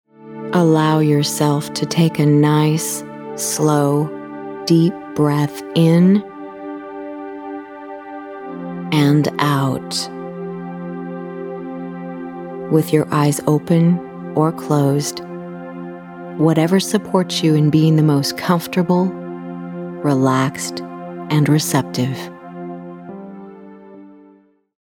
The joy of chocolate just got sweeter with this tranquil experience of radiant bliss and inner peace. In this decadent journey, discover how chocolate can be a remarkably yummy way to bring stillness to the mind and feed the soul.
The frequency of her voice, intentionality, and mindful use of music to heal and soothe, makes for a delicious spa-like, mind melting, and body relaxing journey.